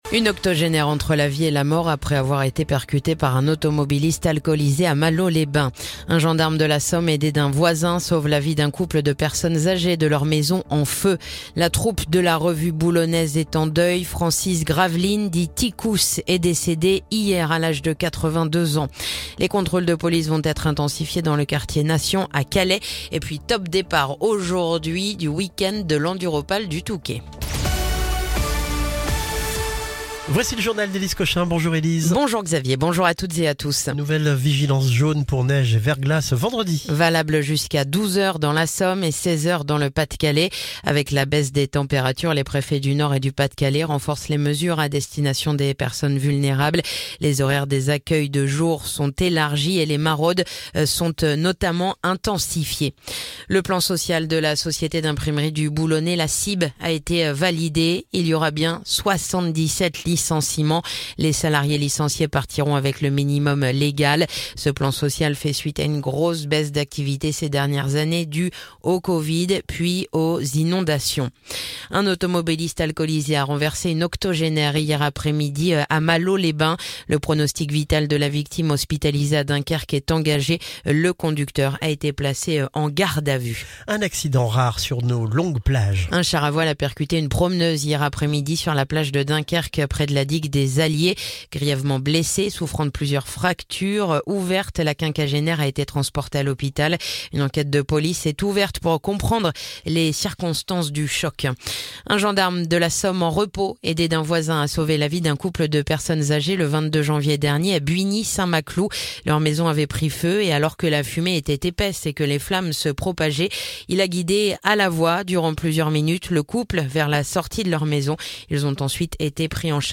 Le journal du vendredi 7 janvier